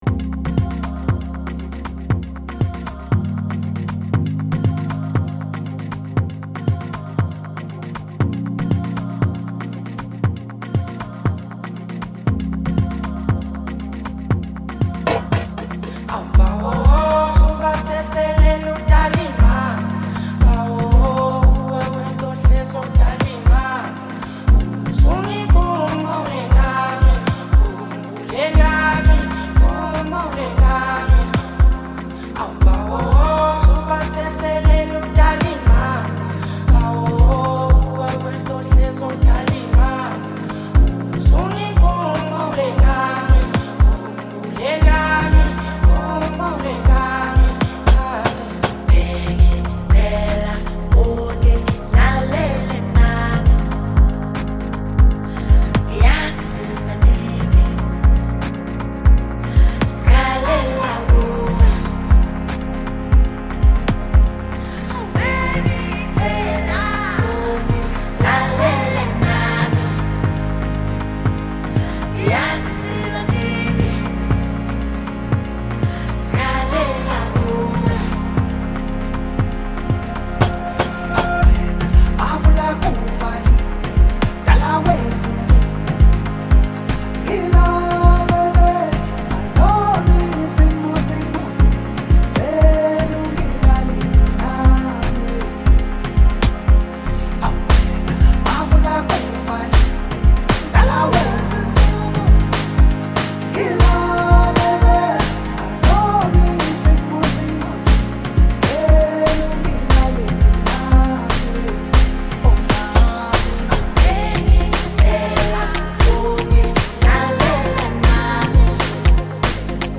Amapiano
• Genre: Amapiano